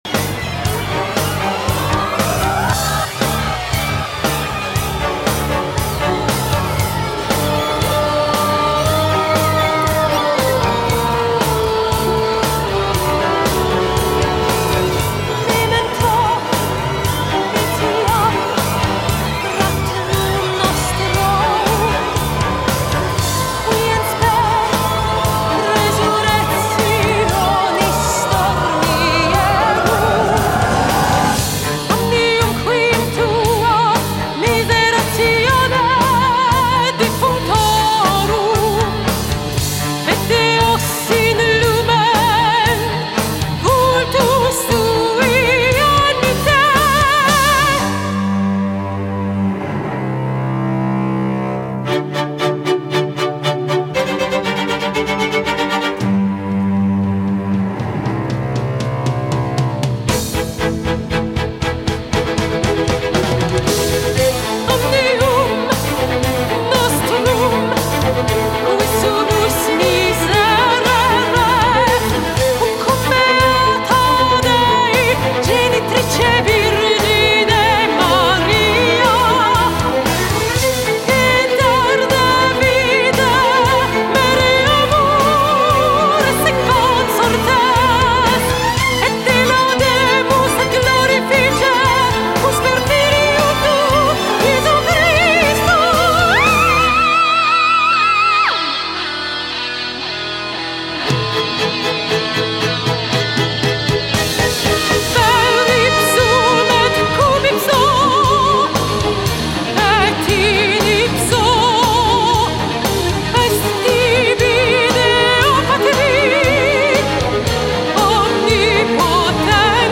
橫跨流行古典、加拿大英法語雙聲跨界音樂新天后
上帝賜予水晶般清澄透澈嗓音獨樹一格
● 這張結合聲樂美學，回歸新世紀空靈，更勝流行音樂質感的誠意專輯，是眾多商業包裝中難得一見清新佳作。